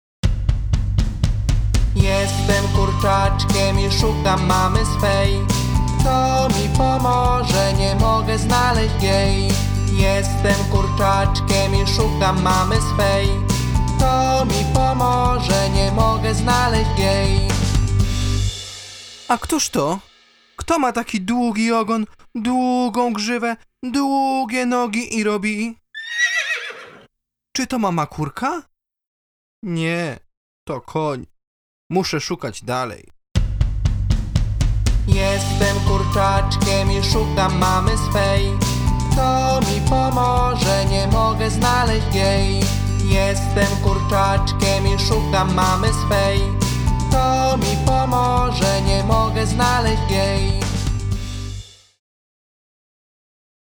Gdy dzieci słyszą dźwięk zwierzęcia – powtarzają go.